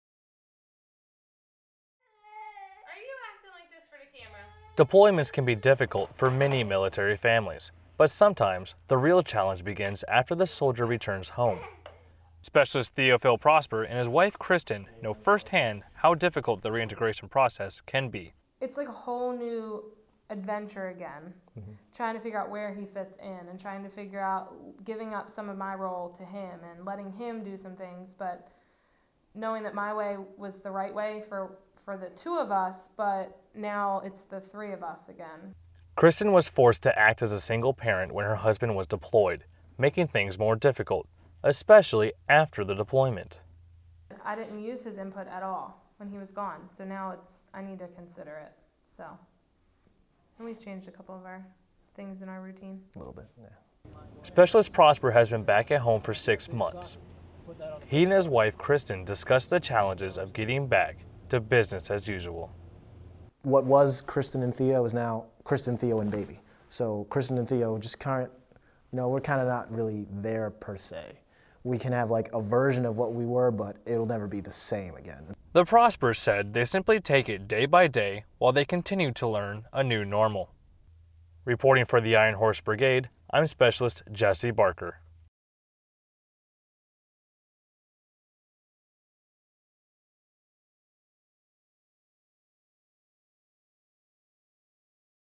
A lot of military families struggle with bringing their deployed loved one back into their daily lives. This story briefly taps into one such couple as they talk about their difficulties and what they did to stay happy.